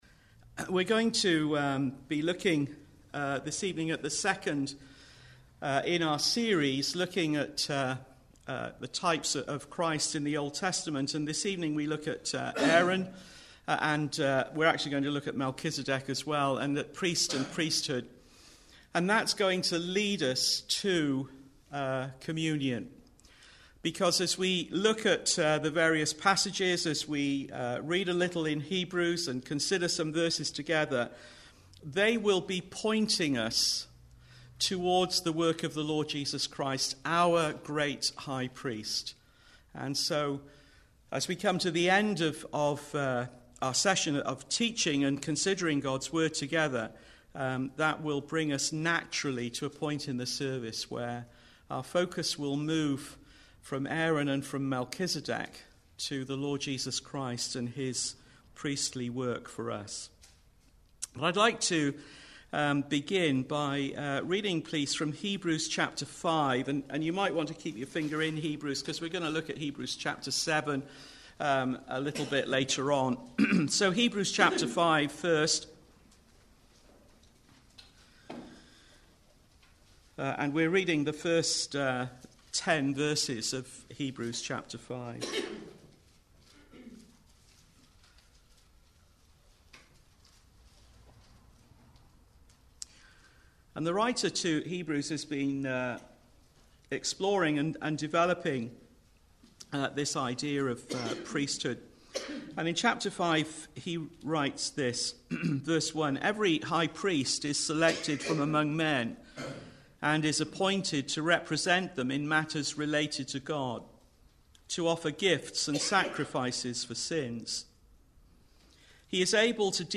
Types of Christ Service Type: Sunday Evening Preacher